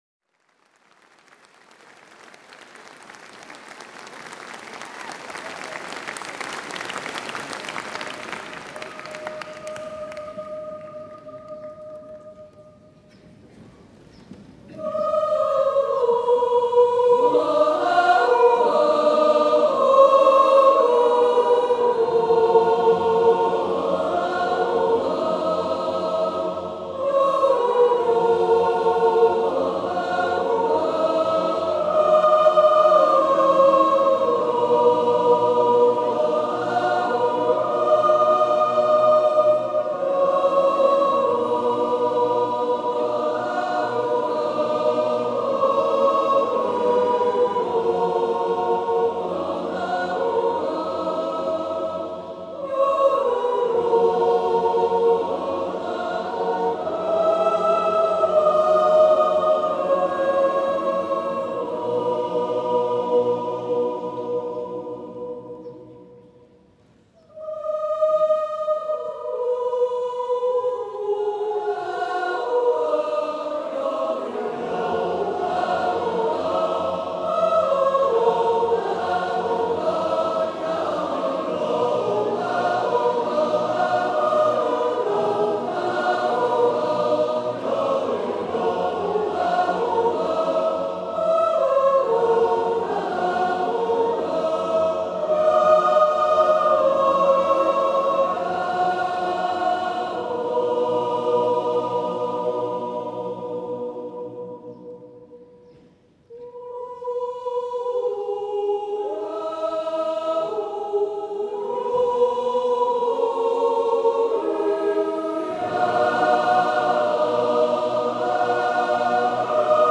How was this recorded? A Swiss National Yodeling Festival